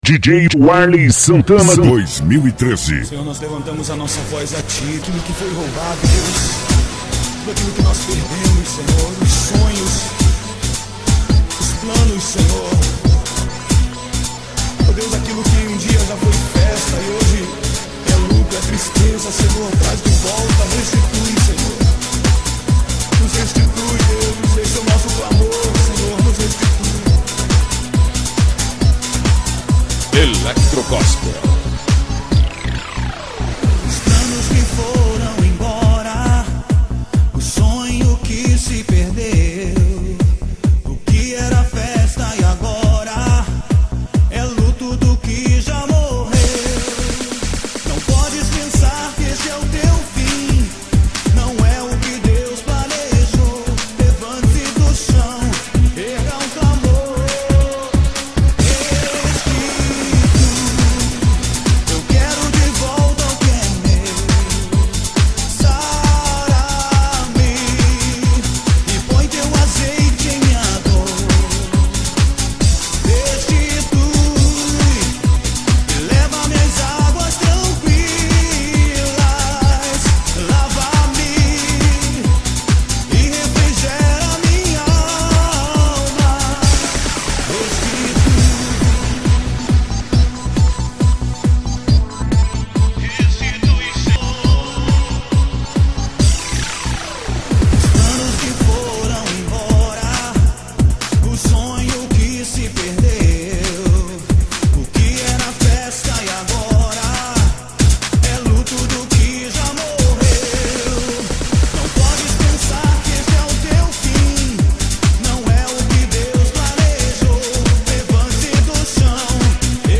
remix.